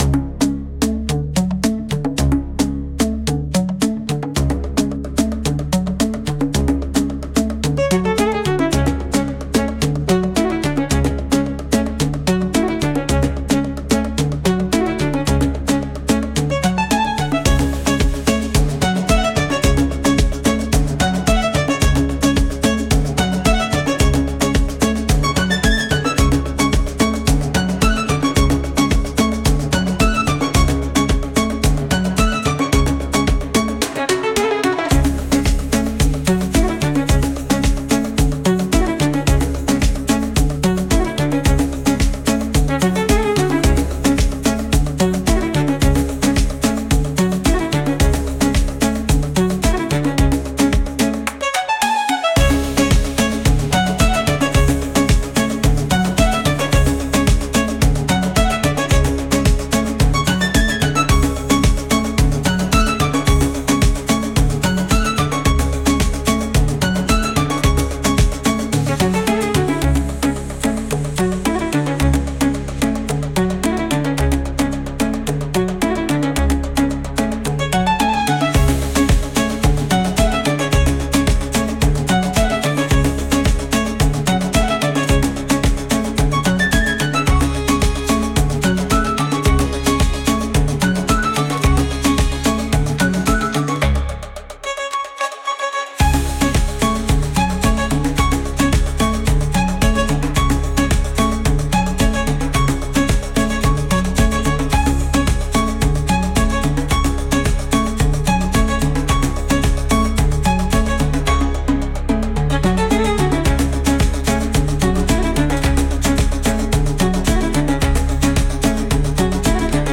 Instrumental / 歌なし
パーカッションが主導する、リズミカルでトライバル（部族的）なアフロビート。